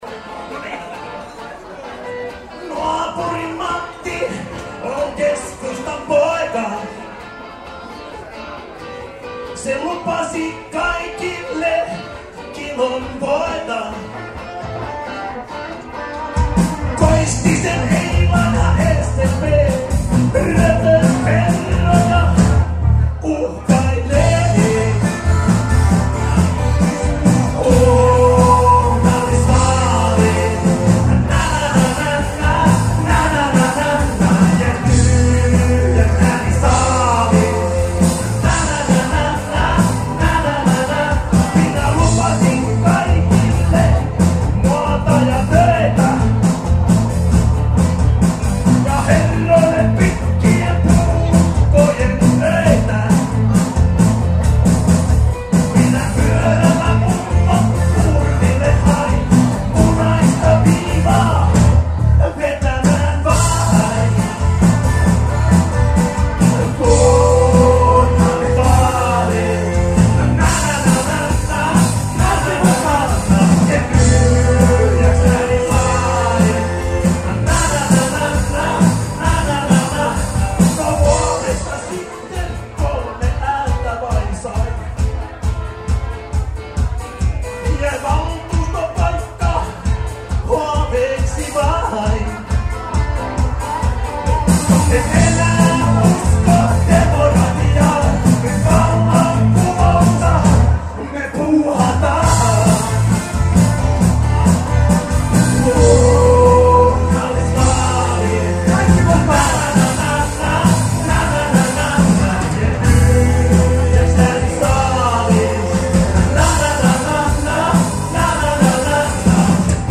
NAUHOITETTU ELÄVÄNÄ JUTTIKSESSA 2.10.2008